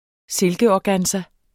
Udtale [ ˈselgəɒˌgansa ]